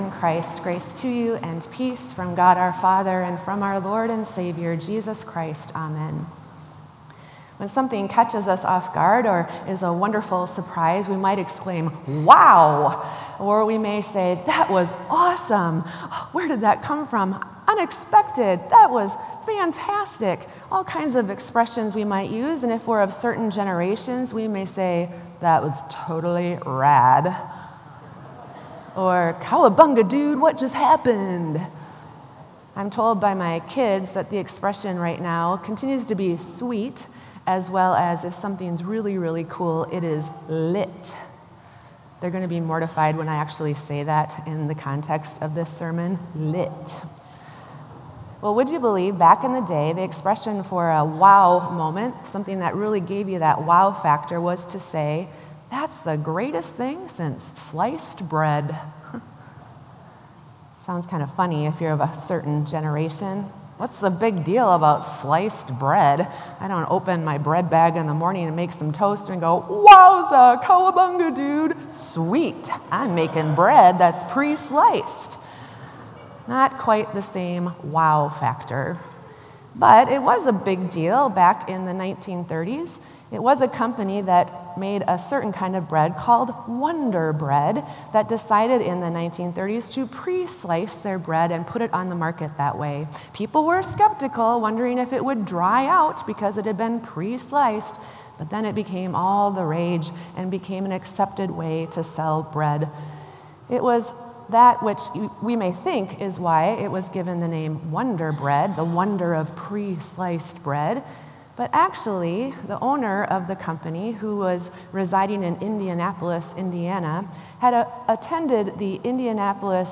Sermon “Wonder Bread”